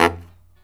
LOHITSAX13-L.wav